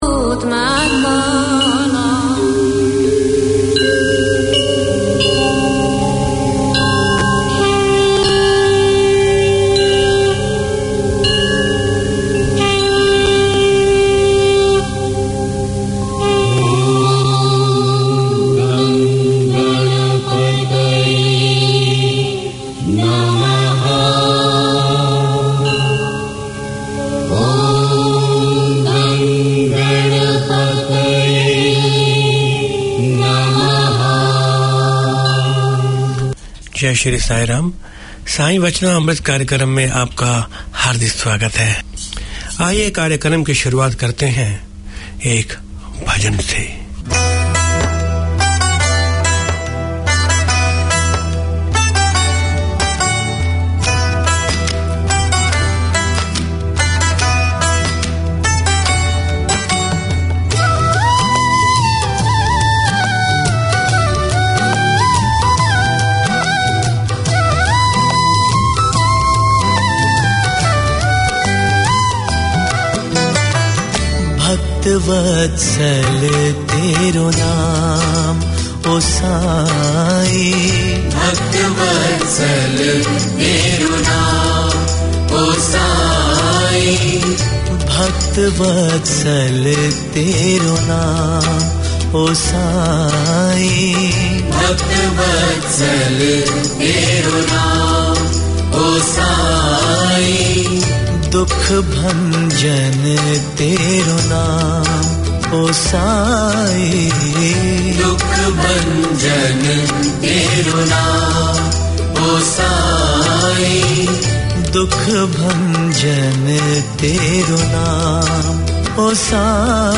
Community Access Radio in your language - available for download five minutes after broadcast.
The programme showcases the history, traditions and festivals of India and Fiji through storytelling and music, including rare Fiji Indian songs.